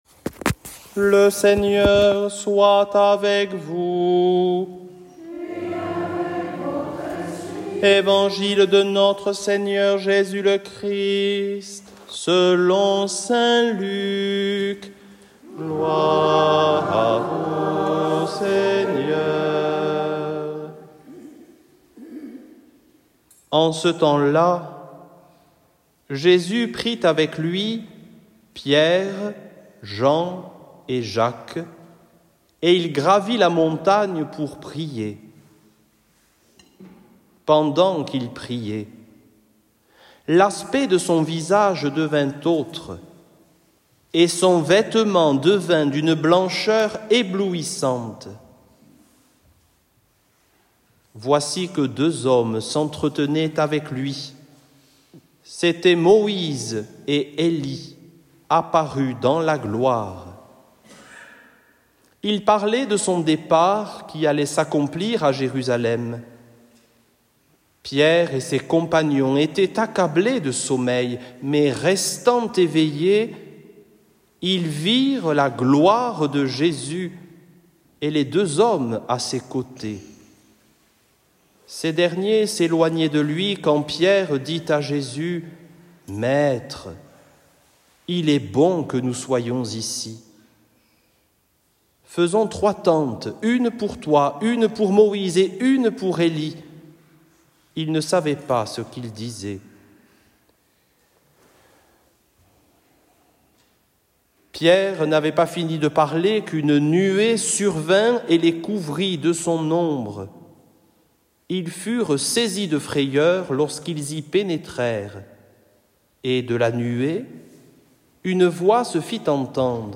evangile-transfiguration-careme-2-2025.mp3